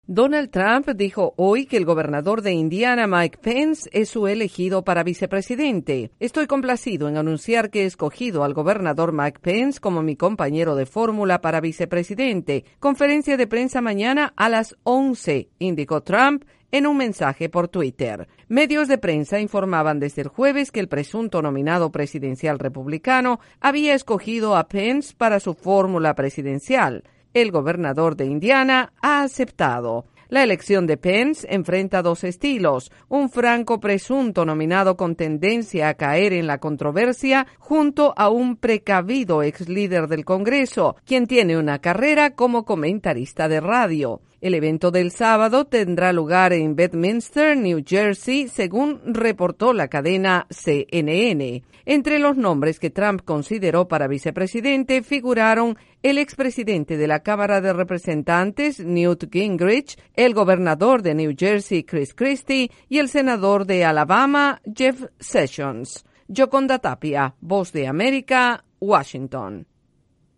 Donald Trump anunció por Twitter el nombre de su candidato para vicepresidente. Desde la Voz de América en Washington DC informa